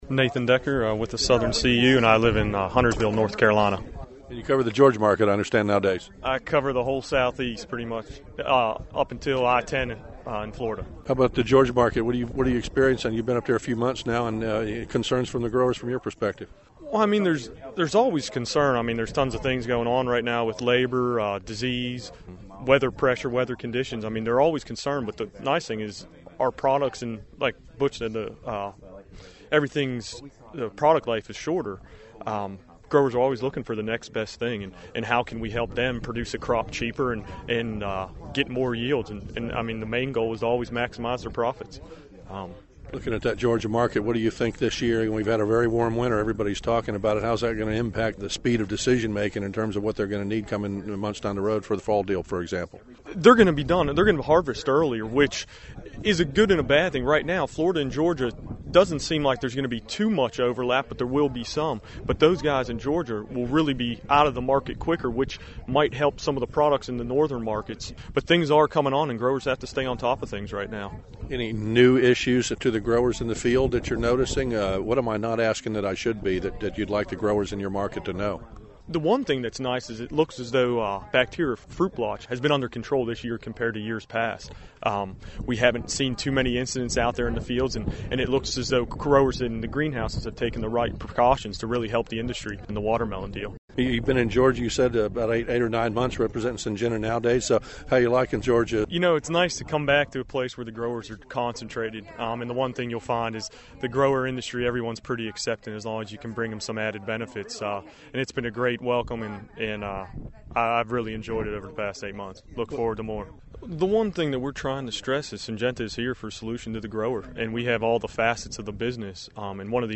Southeastern growers, in this news post you can hear what’s being said in this crowd that will affect what’s grown this year on the farms in this deep southeast region.